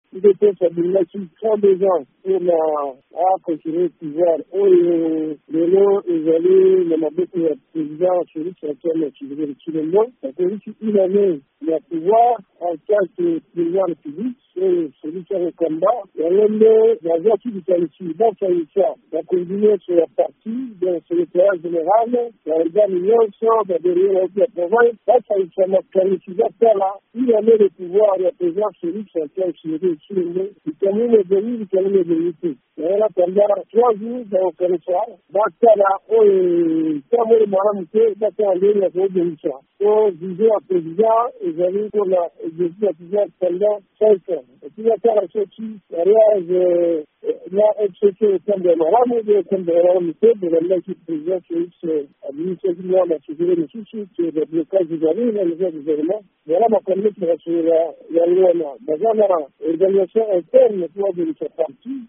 Kati na makambo mazali kosololama, coalition to boyokani na CACH – FCC mpe ezali. VOA Lingala ebengaki mokambi ya lingomba ya UDPS mpe ba allies, Laurent Batumona.